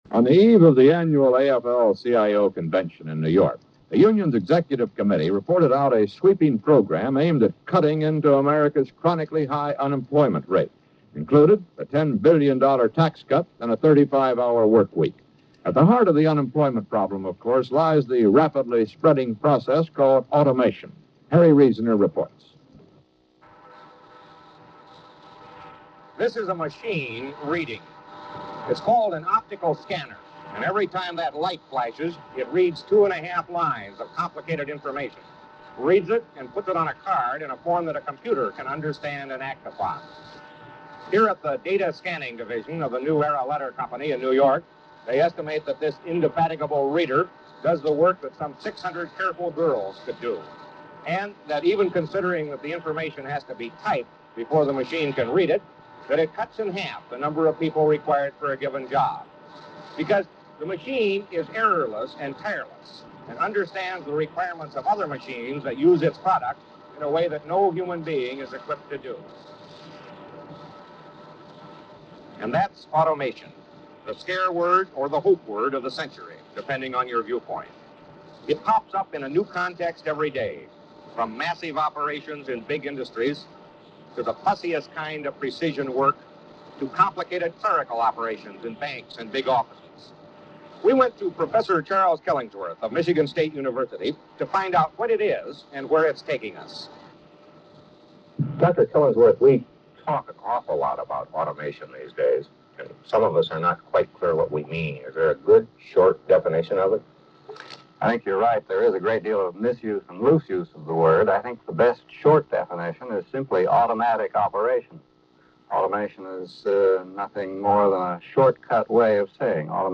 This report, a segment from the CBS Evening News with Walter Cronkite, talks about the increased uses of automation and the potential benefits and pitfalls associated with it. Broadcast on the eve of a major convention of AFofL/CIO members, emphasis is given over to automation and employment.